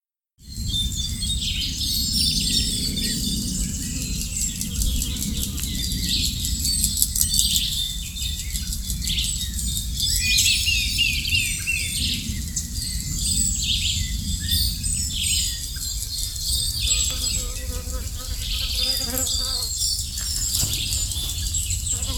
Blacksmith Thrush (Turdus subalaris)
Life Stage: Adult
Location or protected area: Ruta 101 PN Iguazu proximo a Andresito
Condition: Wild
Certainty: Recorded vocal
Zorzal-plomizo.mp3